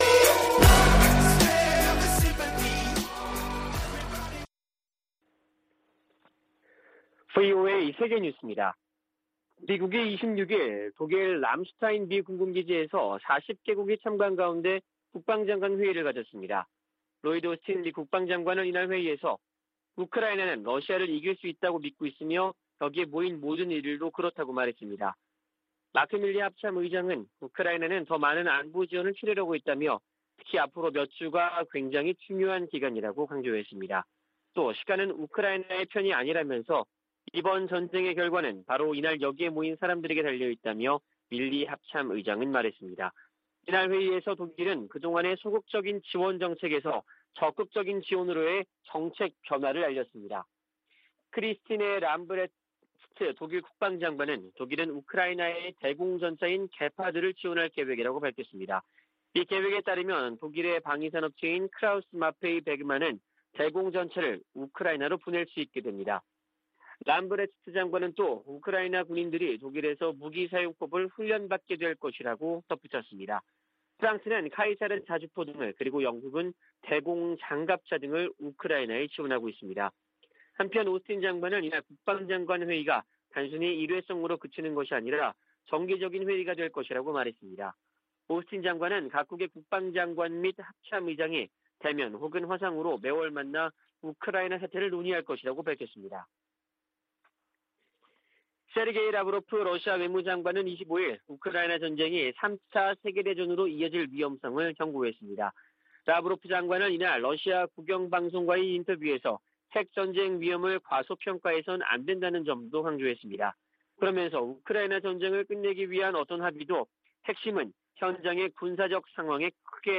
VOA 한국어 아침 뉴스 프로그램 '워싱턴 뉴스 광장' 2022년 4월 27일 방송입니다. 북한이 25일 핵 무력을 과시하는 열병식을 개최했습니다.